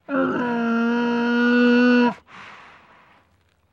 Звуки мула
Стон звука